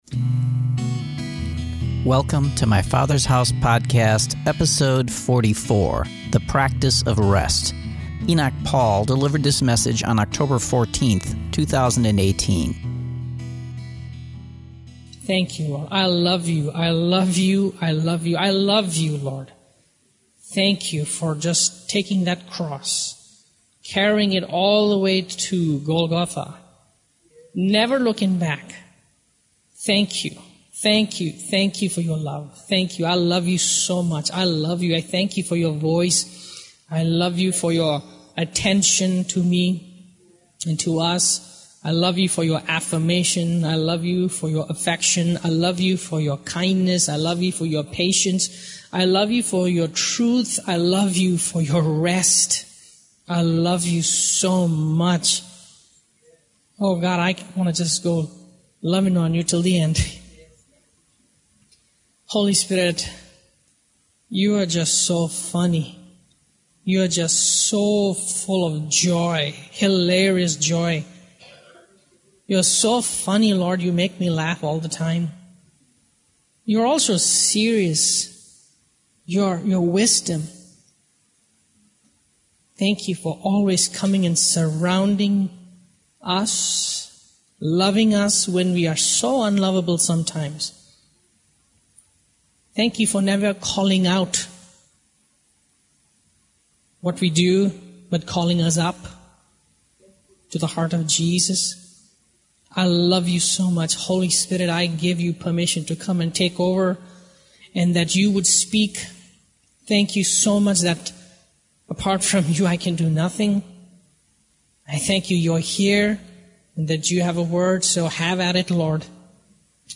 This sermon examines true rest and encourages the people of God to practice it daily and effectively.